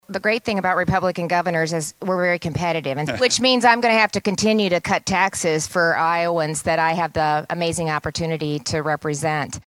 Reynolds and the rest of the nation’s governors are meeting in Washington, D.C. this weekend and the governor made her comments at a forum sponsored by the Cato Institute, a libertarian think tank. Reynolds says 15 of her fellow Republican governors are proposing tax reductions in their states this year.